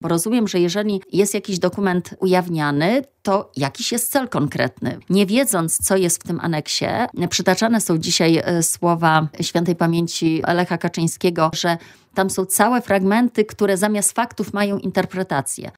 Cała rozmowa w materiale wideo: